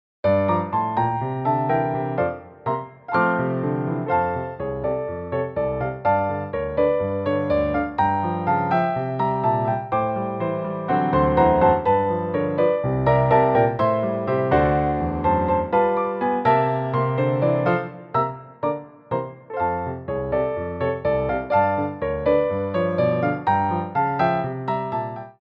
Medium Allegro
4/4 (8x8)